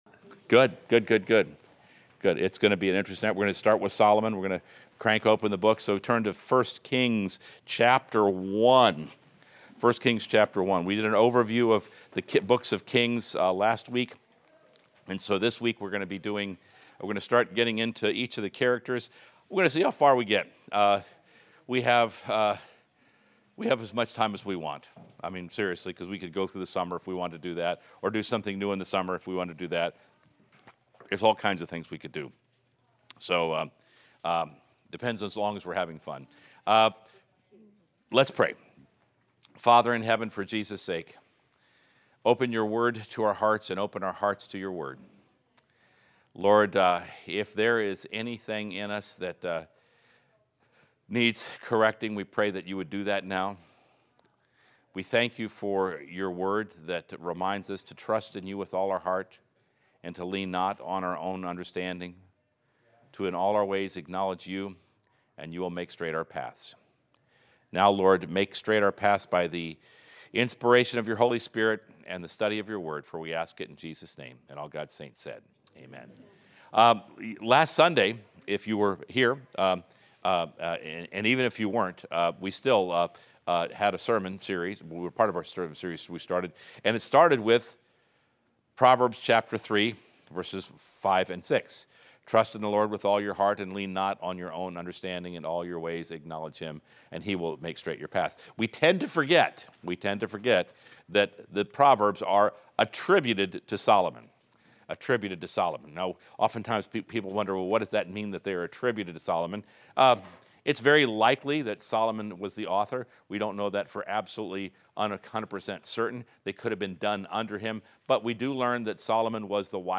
Experience the Word Bible Study